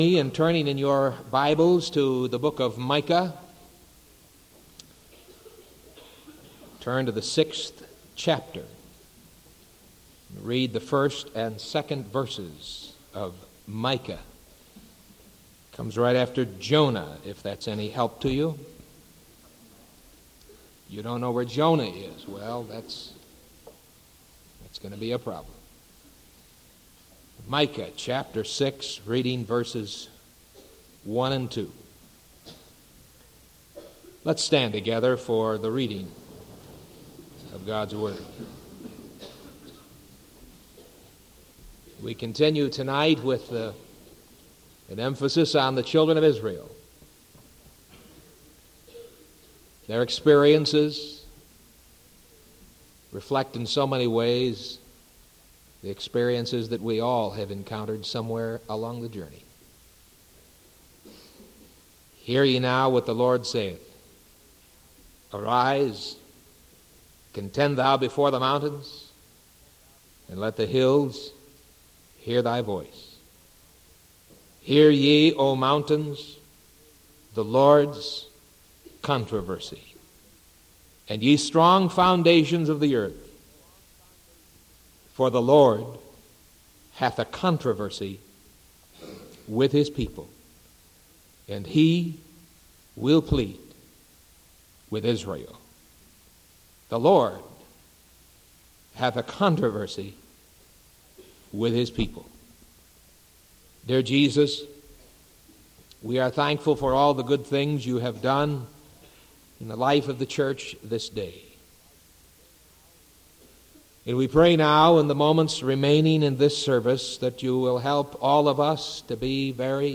Sermon January 29th 1978 PM